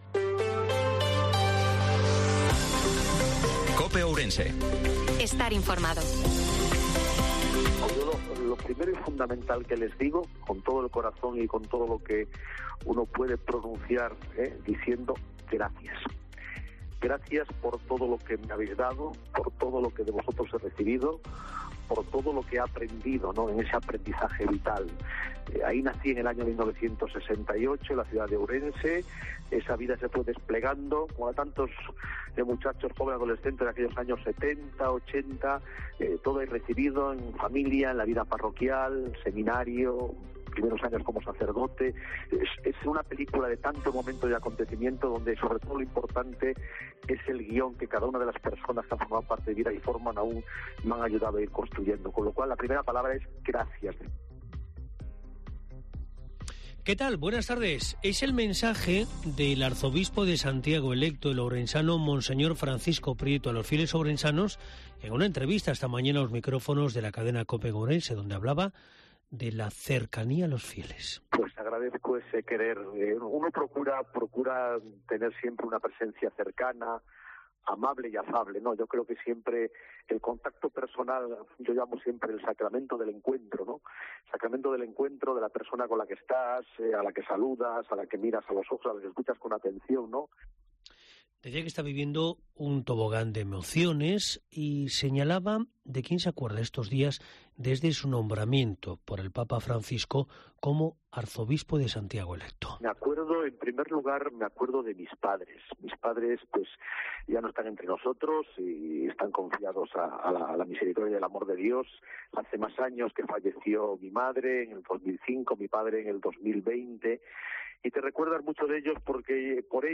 INFORMATIVO MEDIODIA COPE OURENSE-05/04/2023